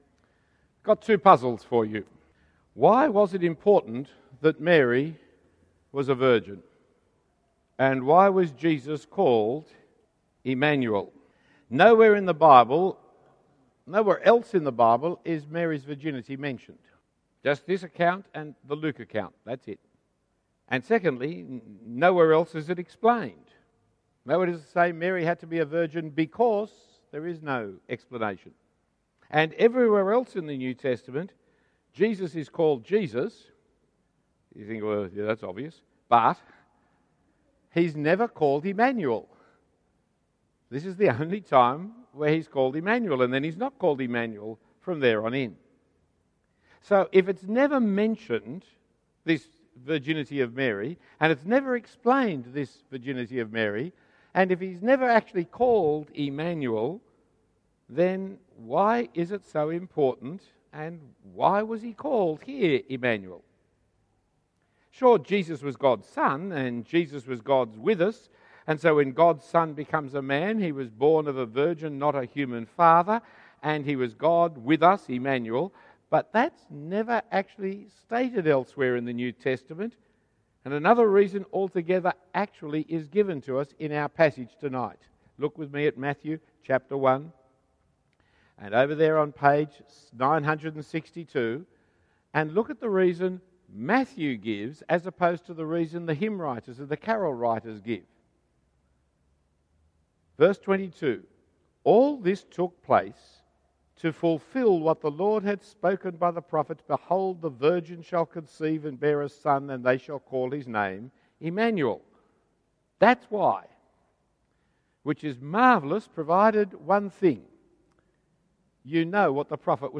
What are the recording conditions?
A Christmas sermon given at City Night Church, St Andrew’s Cathedral in 2013.